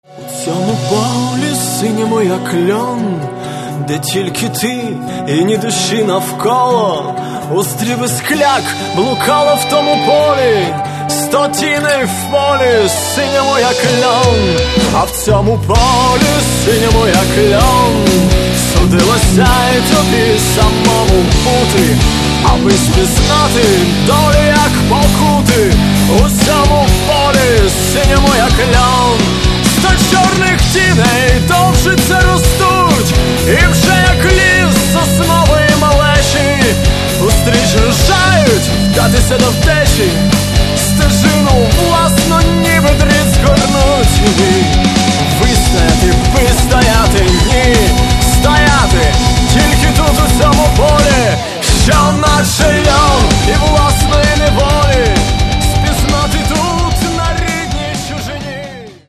альтернативного фолк-рока